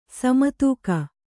♪ sama tūka